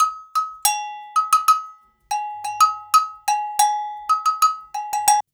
90 AGOGO04.wav